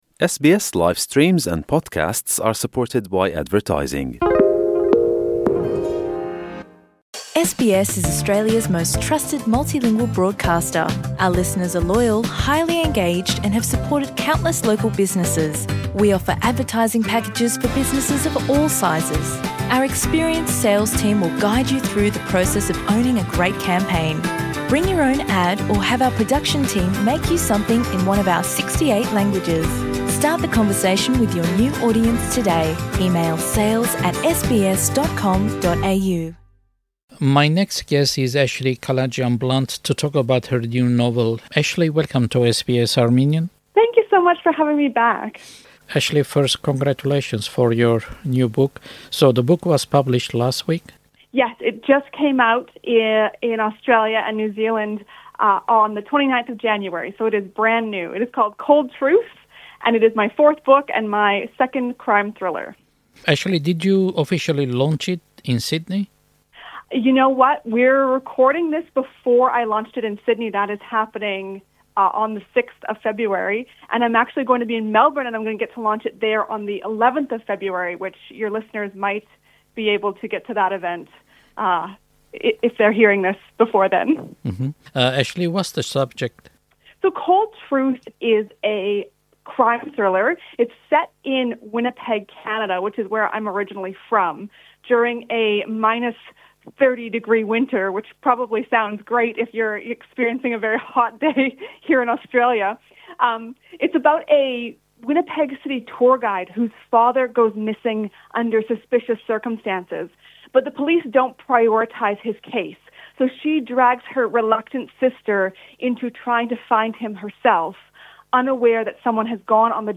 Cold Truth: Interview